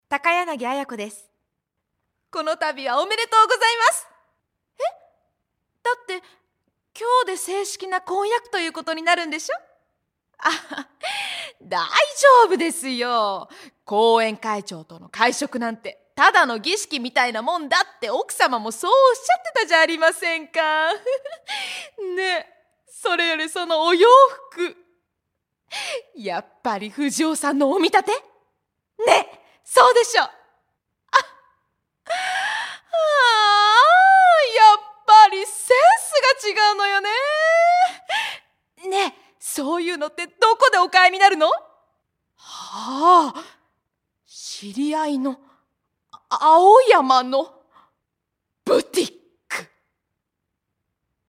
‘‚Á‚Û‚¢—«